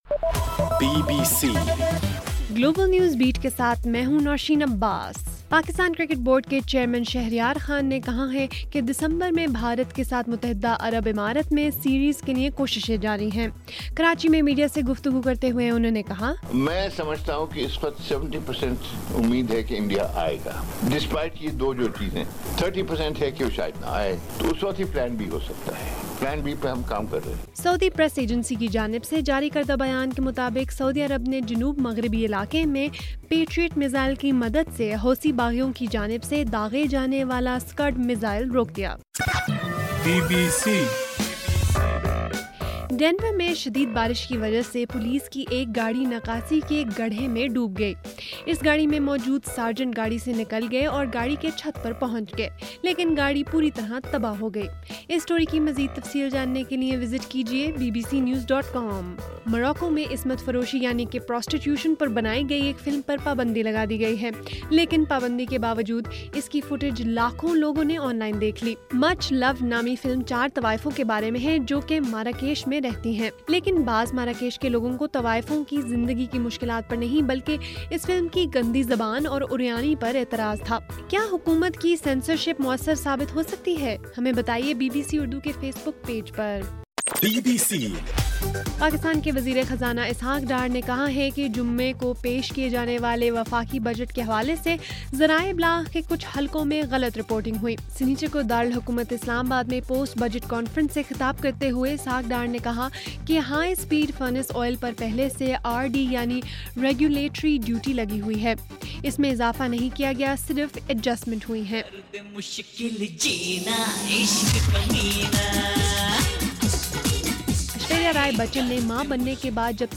جون 6: رات 11بجے کا گلوبل نیوز بیٹ بُلیٹن